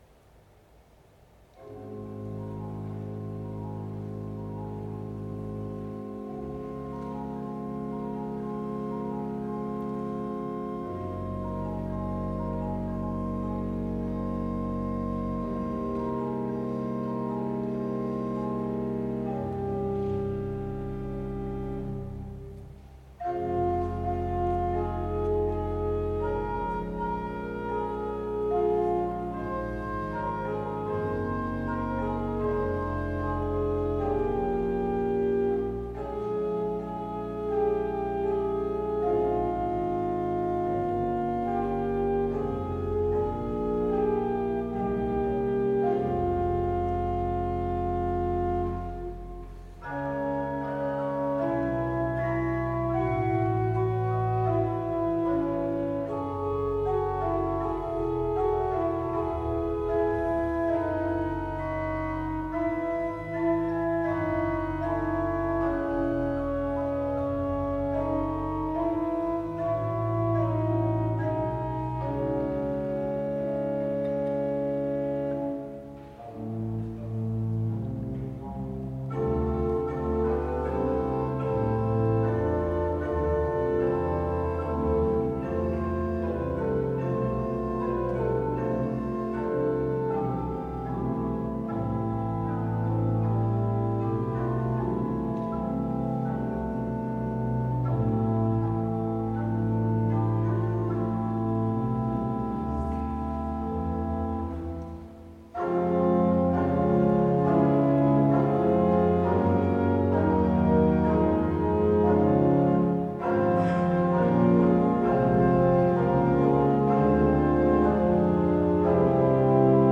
Tällä sivulla soivat Järvenpään kirkon Kangasala-urut vuodelta 1968.
Järvenpään kirkon urut ovat soinnillisilta ominaisuuksiltaan edustava 1960-luvun soitin.
huiluja ja principaleja (Arro, Eesti rahvaviise)